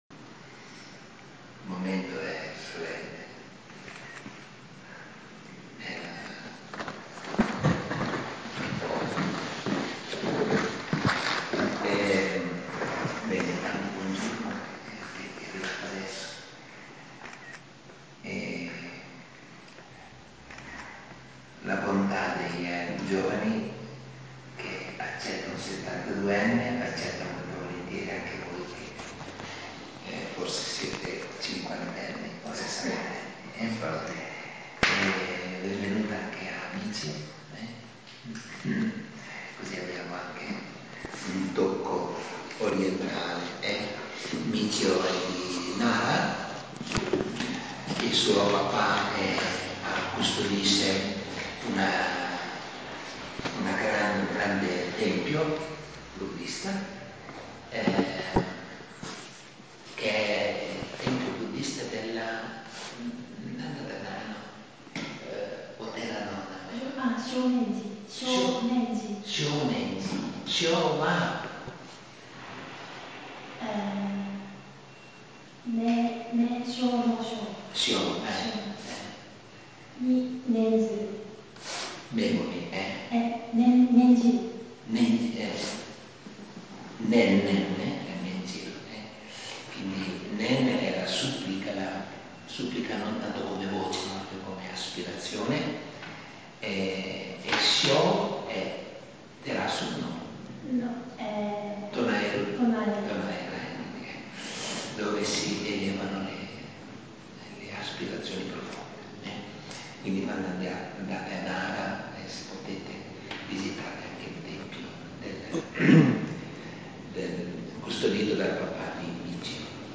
Al Ritiro Vangelo e Zen di Desio - Vangelo e Zen - comunità di dialogo interreligioso
Pubbliciamo un resoconto fotografico di alcuni momenti, un breve video e sopratutto le registrazioni audio delle due conferenze di Venerdì 29 luglio, sul tema dell’Estetica della vita come scaturisce dallo Zen nel dialogo con il Vangelo, presentate rispettivamente